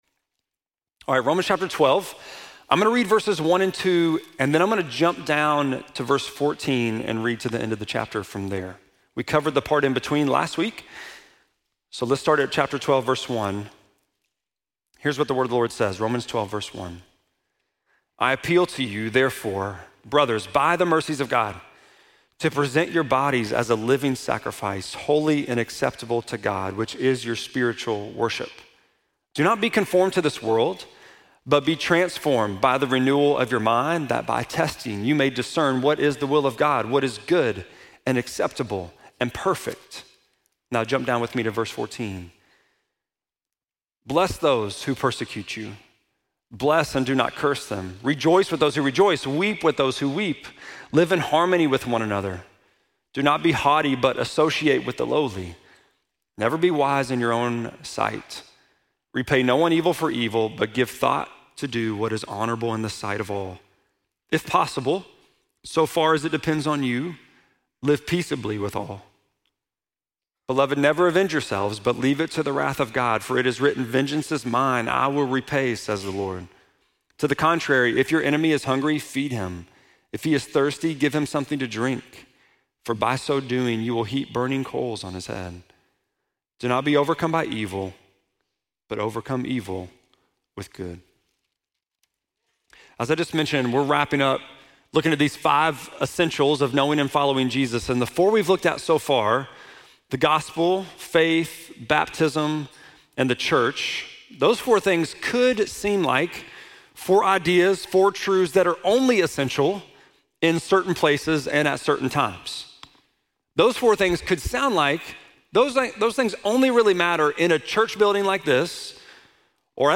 8.31-sermon.mp3